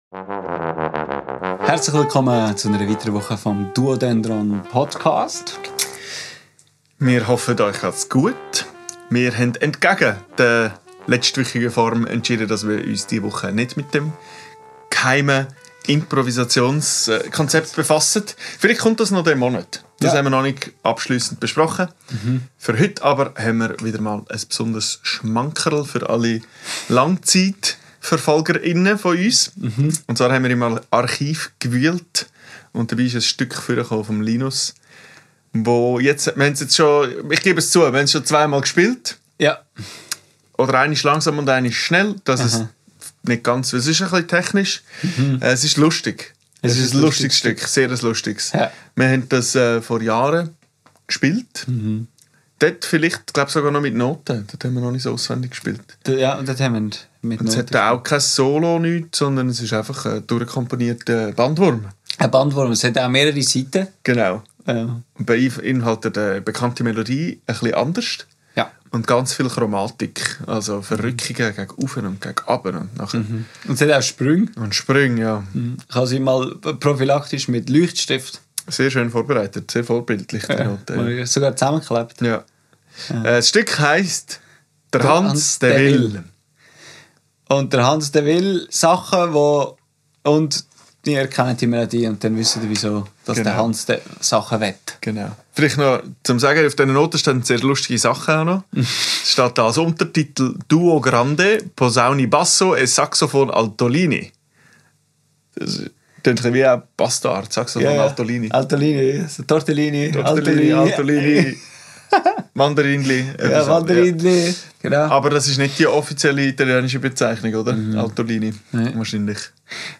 eine lustige Version vom Lied